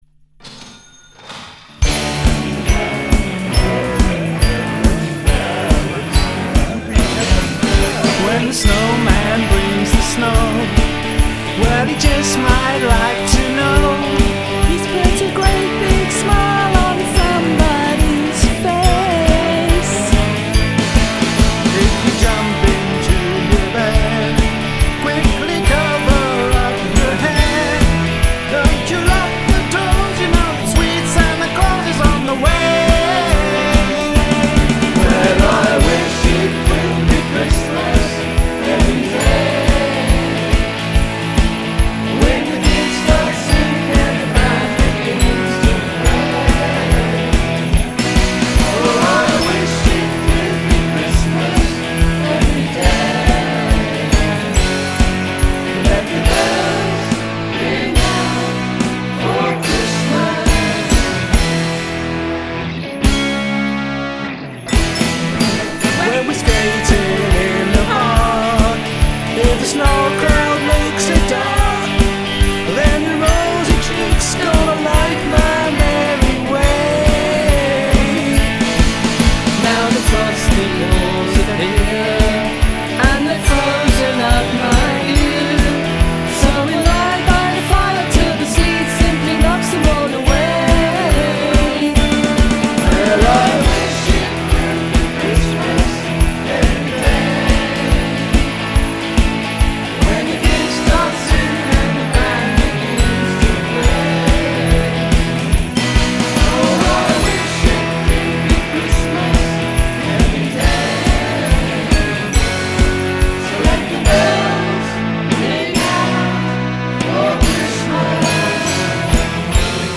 Bass
Drums/Guitar